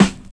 18 SNARE  -R.wav